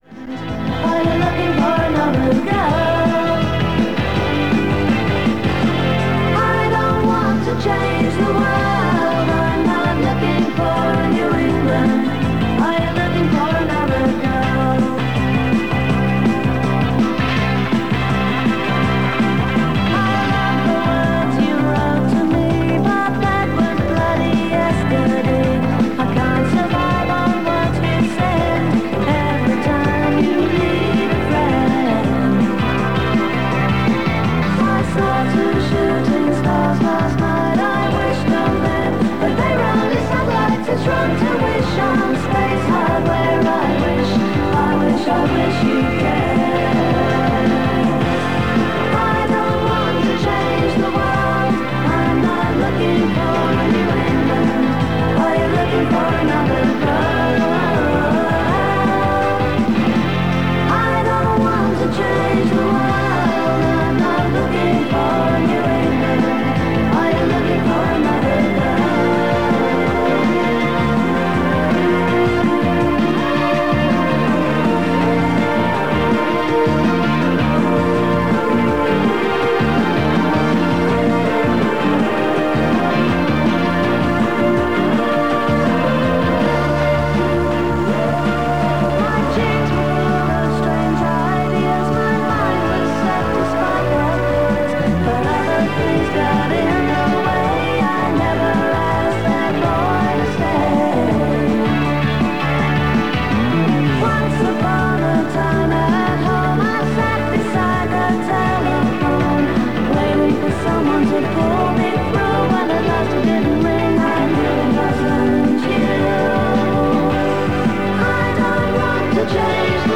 This recording is of popular DJ Gareth O’Callaghan presenting the drivetime show on a balmy summer evening in 1986. The show includes gig and event guides, a teaser, birthday slot, weather forecasts from Dublin and across Europe and the usual agency adverts associated with Energy 103.
The recording was made from 103 FM from 1805-1853 on 7th July 1986 and is from the Anoraks Ireland Collection.